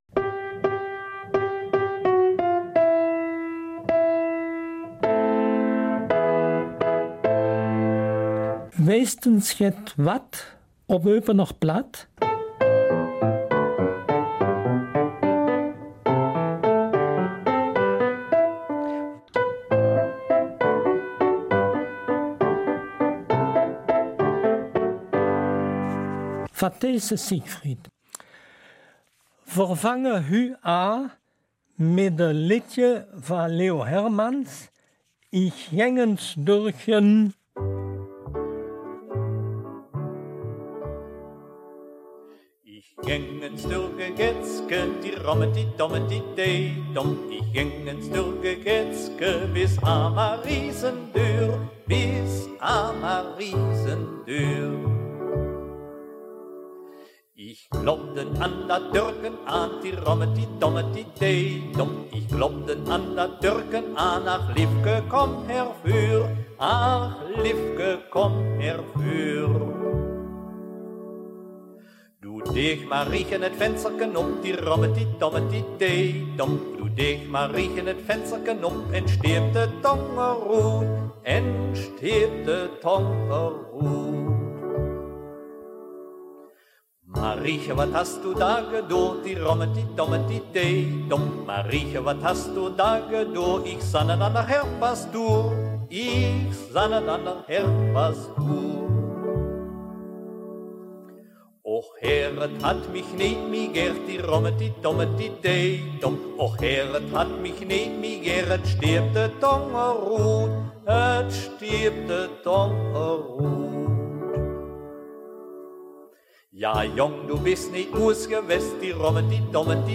Eupener Mundart - 9. Januar